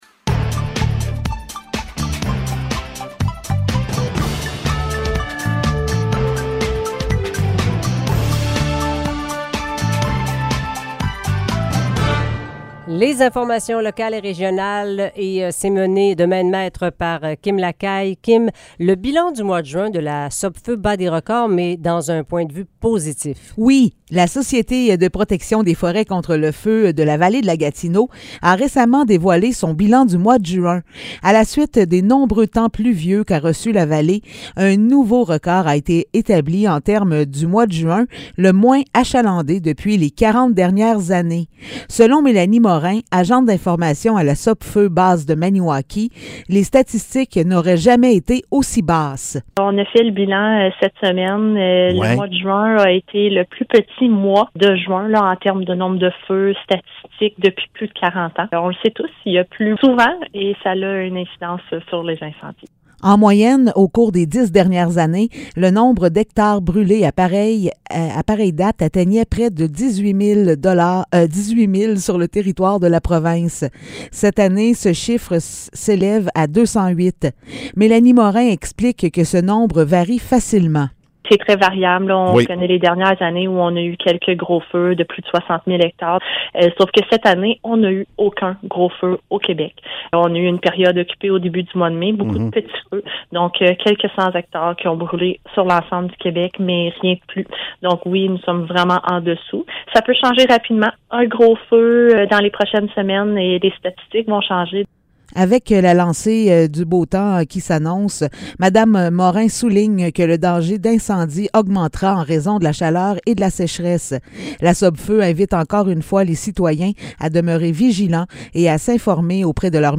Nouvelles locales - 8 juillet 2022 - 15 h